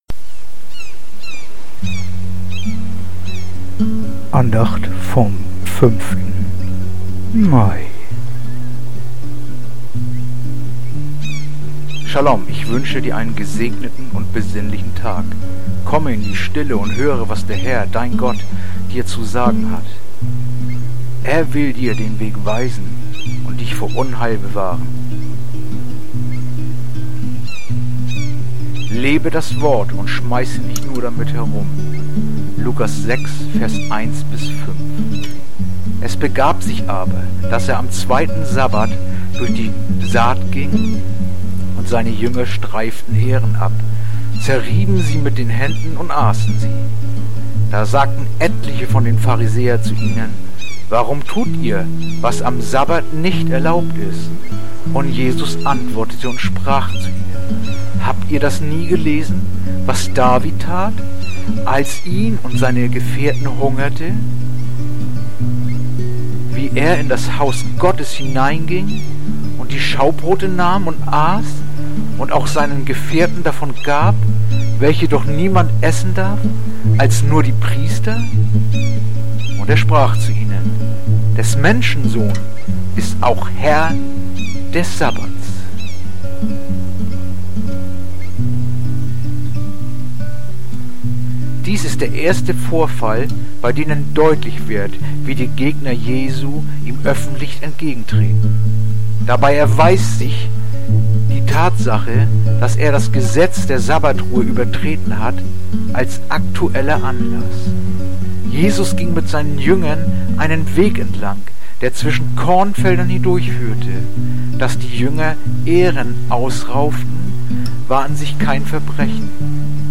heutige akustische Andacht
Andacht-vom-05.-Mai-Lukas-6-1-5.mp3